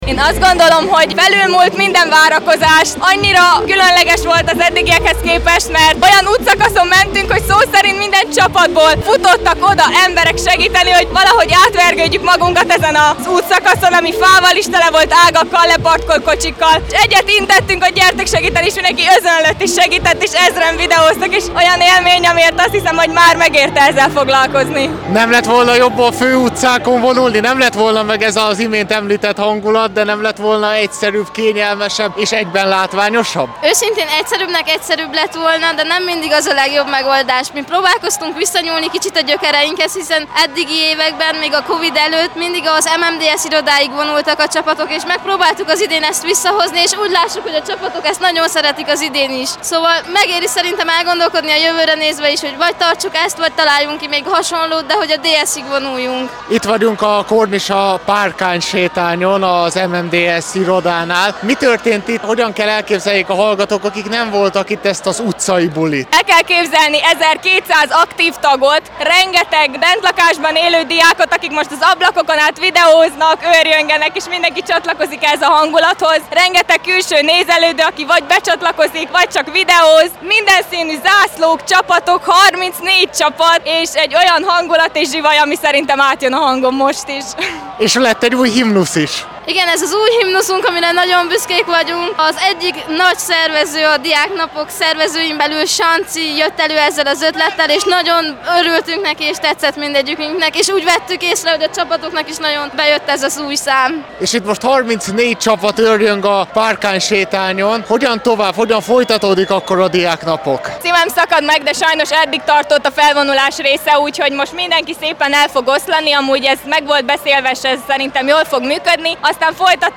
A felvonulásról és Diáknapok további programjairól beszélgetett